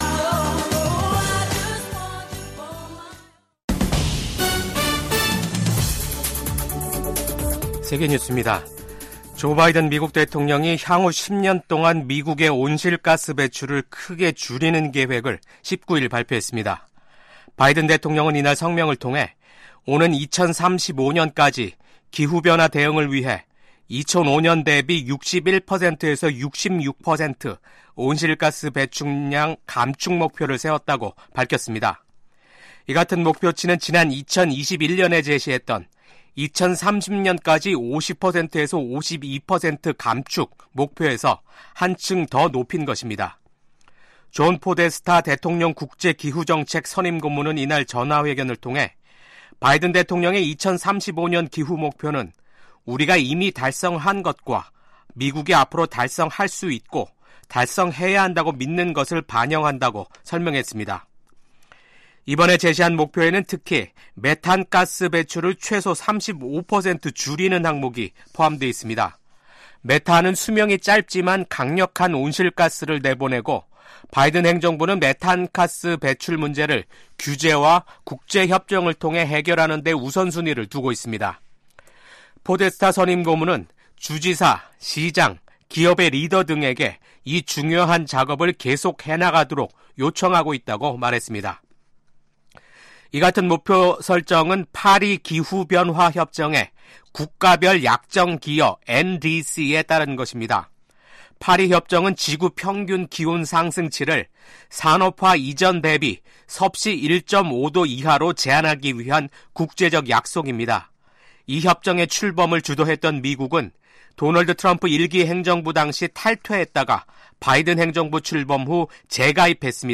VOA 한국어 아침 뉴스 프로그램 '워싱턴 뉴스 광장'입니다. 한국 국가정보원은 우크라이나 전쟁에 파견된 북한 군 병사들의 전사 사실을 확인했습니다. 미국과 한국 등 유엔 안보리 이사국들이 북한의 무기 개발과 러시아에 대한 병력 파병과 무기 제공을 강하게 규탄했습니다. 미국 상원의원들은 중국이 한국의 정치적 불안정을 이용해 윤석열 대통령의 대중 정책을 바꾸고 역내 질서를 재편하려 할 가능성에 대한 우려를 표했습니다.